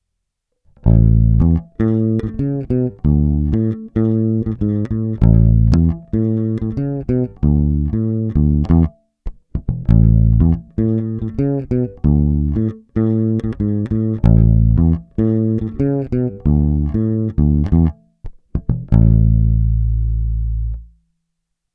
Není-li řečeno jinak, následující nahrávky jsou vyvedeny rovnou do zvukové karty, normalizovány a jinak ponechány bez dalších úprav.
Následující tři ukázky jsou hrány s korekcemi ve střední poloze a vždy nad aktivním snímačem, v případě obou pak mezi nimi.
Oba snímače